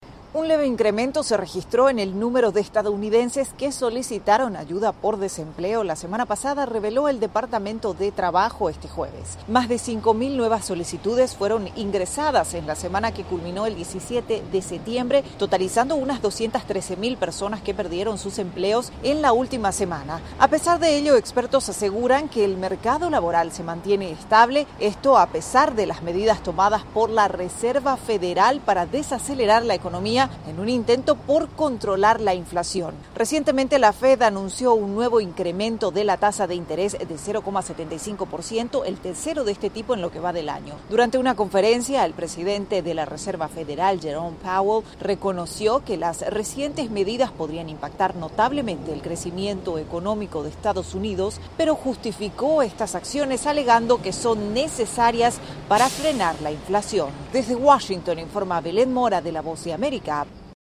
desde la Voz de América en Washington DC.